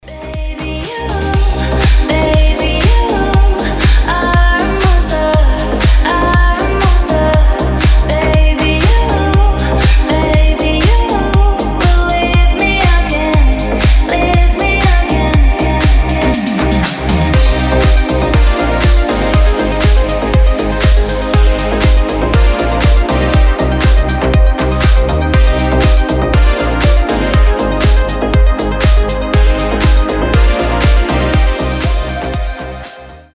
• Качество: 320, Stereo
deep house
мелодичные
женский голос
nu disco